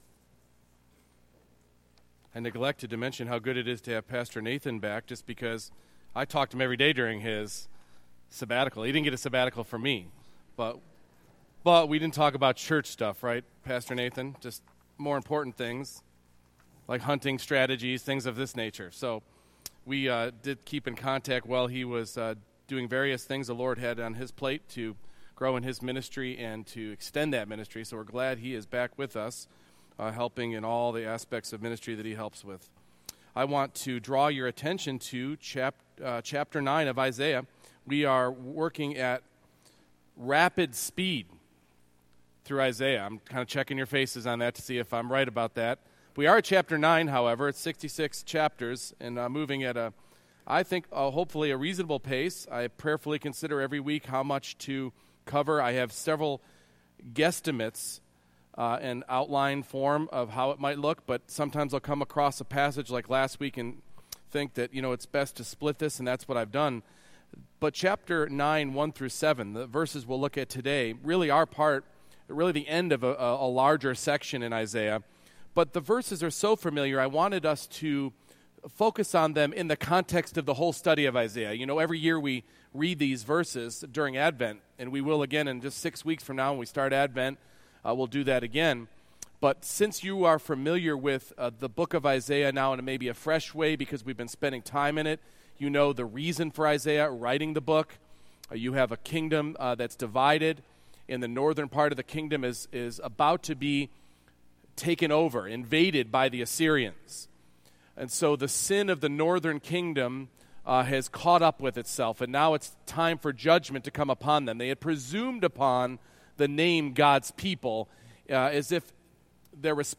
Isaiah 9:1-7 Service Type: Morning Worship Jesus came to dispel the darkness of the world and your life.